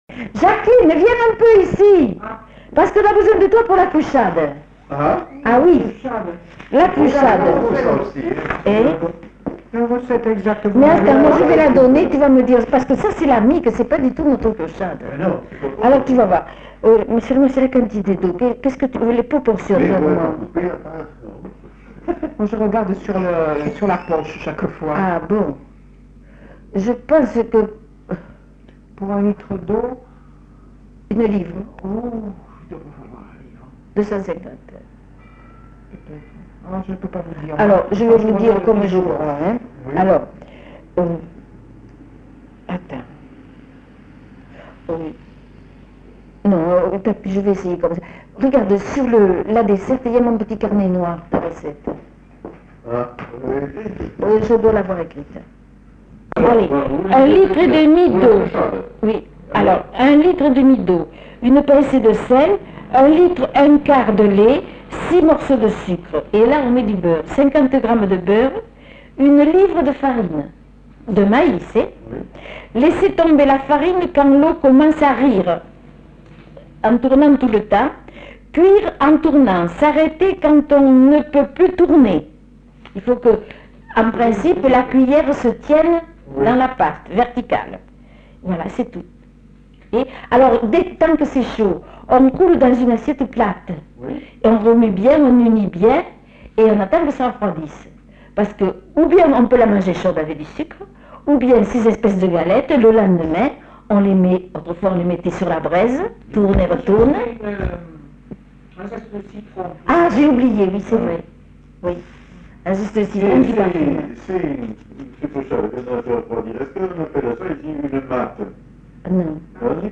Aire culturelle : Bazadais
Lieu : La Réole
Genre : témoignage thématique